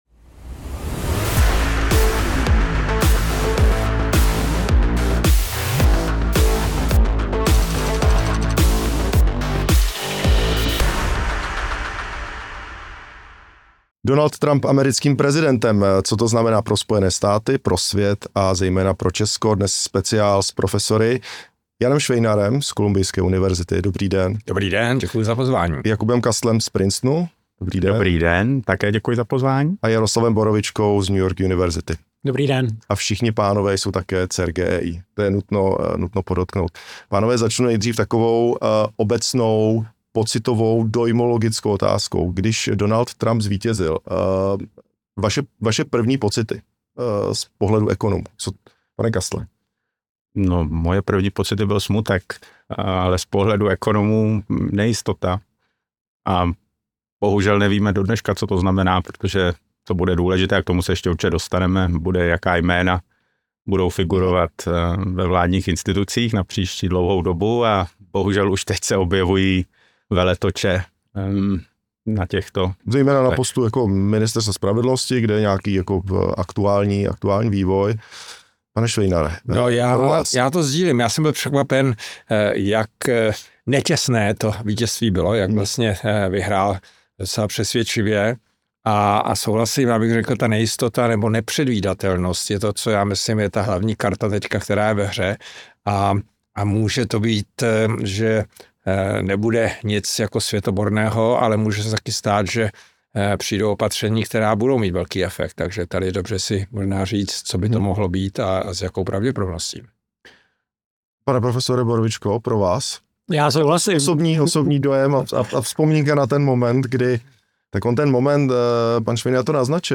Podívejte se na víc než hodinovou diskuzi